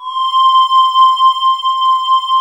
Index of /90_sSampleCDs/USB Soundscan vol.28 - Choir Acoustic & Synth [AKAI] 1CD/Partition D/26-VOCOSYNES